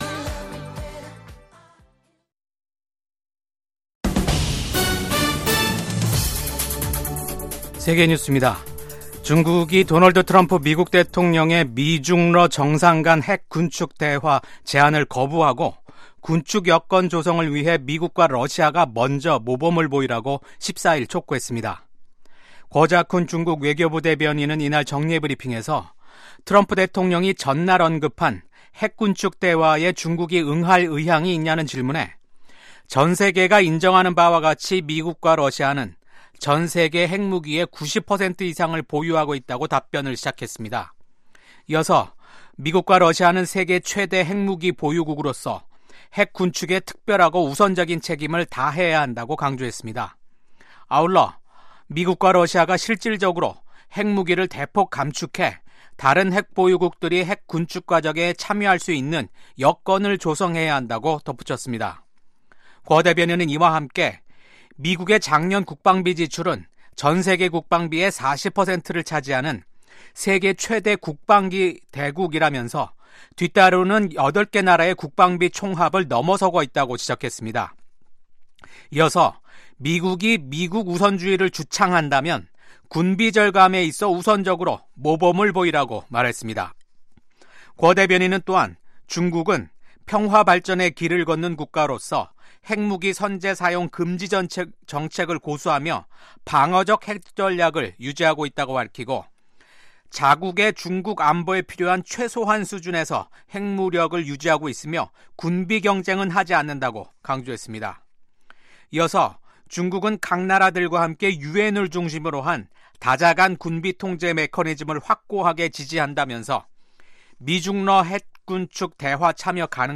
VOA 한국어 아침 뉴스 프로그램 '워싱턴 뉴스 광장'입니다. 북한이 남북 화해의 상징인 금강산 관광지구 내 이산가족면회소를 철거 중인 것으로 파악됐습니다. 미국 군함을 동맹국에서 건조하는 것을 허용하는 내용의 법안이 미국 상원에서 발의됐습니다. 미국 경제 전문가들은 트럼프 대통령의 철강∙알루미늄 관세 부과는 시작에 불과하다면서 앞으로 더 많은 관세가 부과돼 미한 경제 관계에 긴장이 흐를 것으로 전망했습니다.